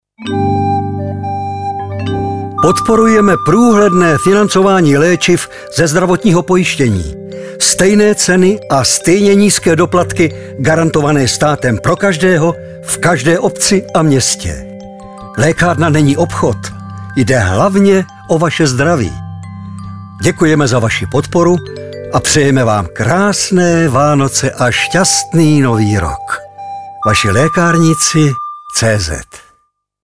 Hlasem Vašich lékárníků je i nadále charismatický herec Ladislav Frej.